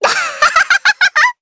Rehartna-Vox_Happy3_jp.wav